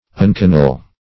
Search Result for " anconeal" : The Collaborative International Dictionary of English v.0.48: Anconal \An"co*nal\, Anconeal \An*co"ne*al\, a. (Anat.) Of or pertaining to the ancon or elbow.